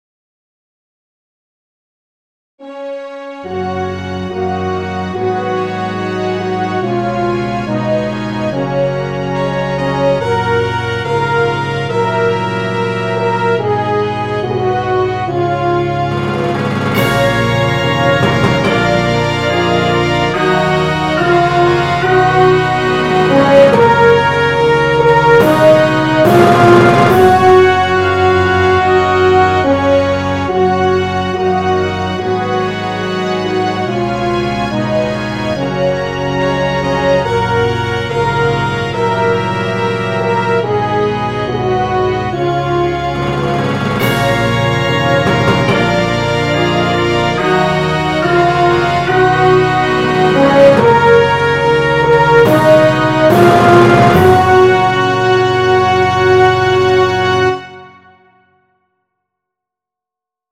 檔案 檔案歷史 檔案用途 Our_Homeland_Liéde.mp3  （MP3音訊檔，總共長1分1秒，位元速率128 kbps，檔案大小：946 KB） 摘要 State Anthem of Liede 檔案歷史 點選日期/時間以檢視該時間的檔案版本。